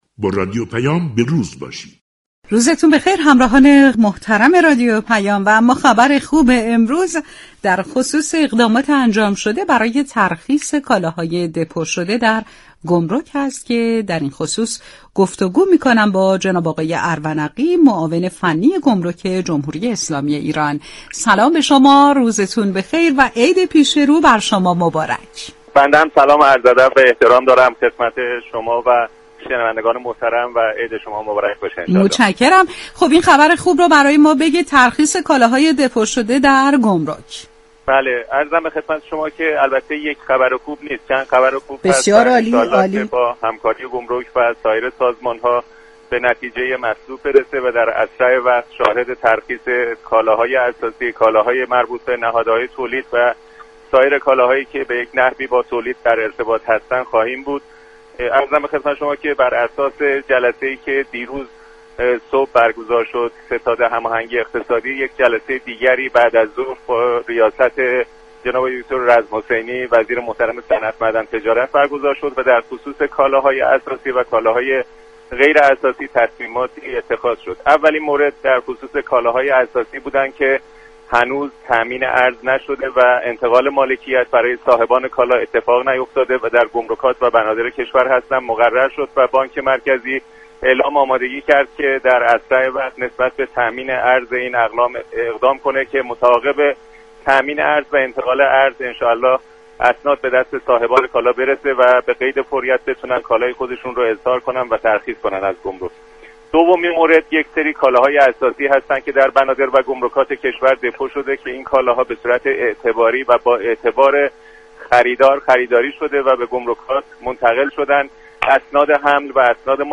ارونقی، معاون فنی و امور گمركی گمرك جمهوری اسلامی ایران، در گفتگو با رادیو پیام جزئیات اقداماتی كه برای ترخیص فوری كالاهای دپو شده در گمركات انجام شده را بازگو كرد.